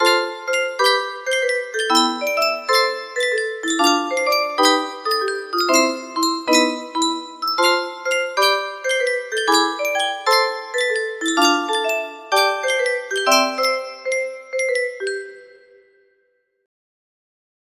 something music box melody